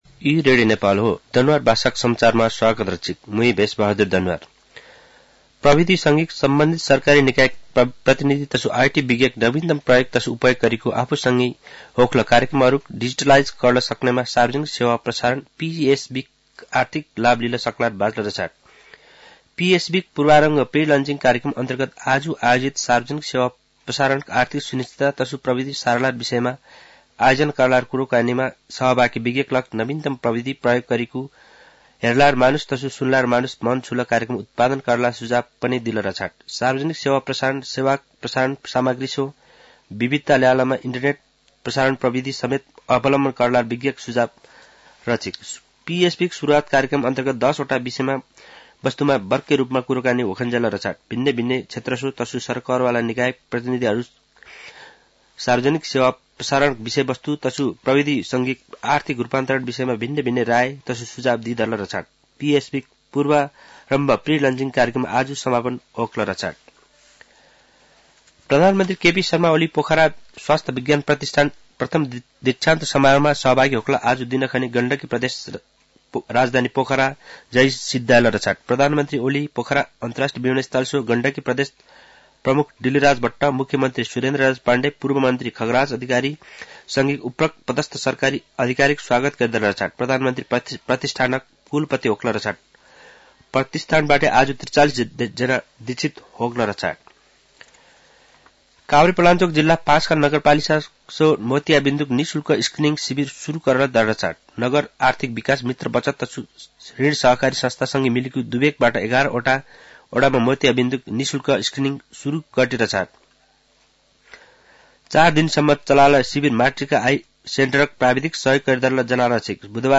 दनुवार भाषामा समाचार : ४ माघ , २०८१
Danuwar-news-1-2.mp3